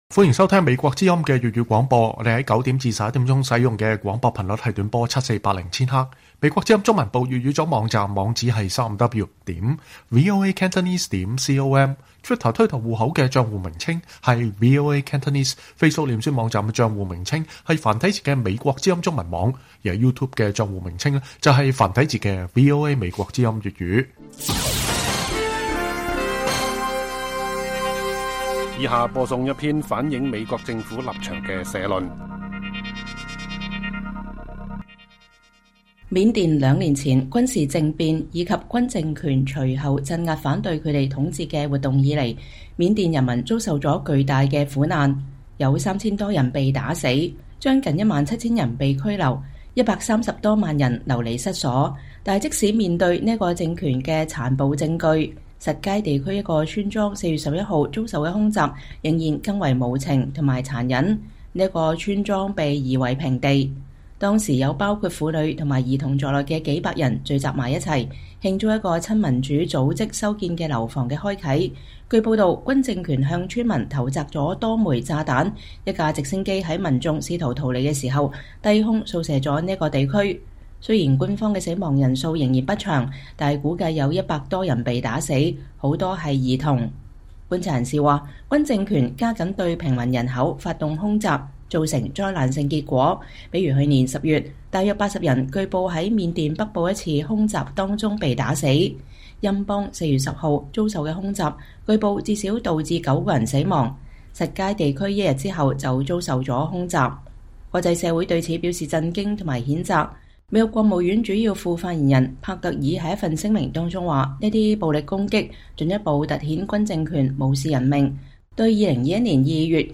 美國政府立場社論